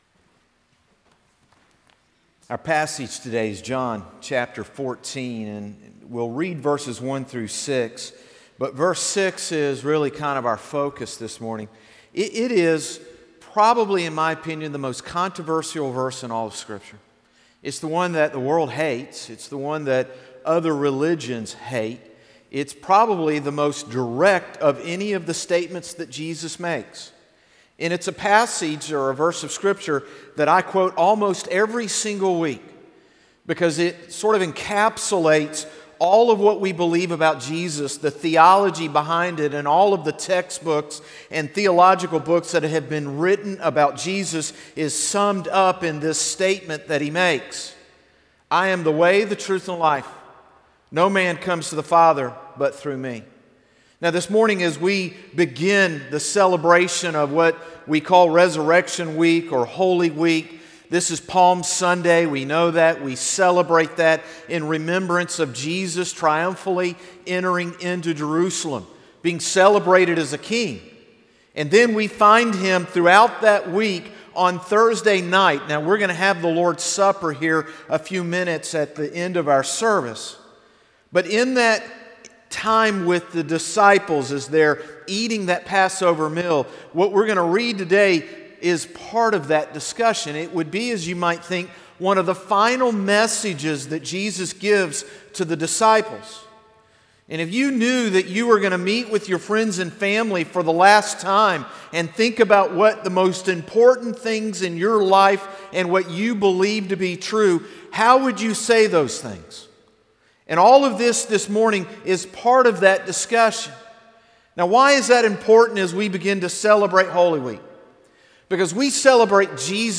Morning Service - Jesus Is the Only Way | Concord Baptist Church
Sermons - Concord Baptist Church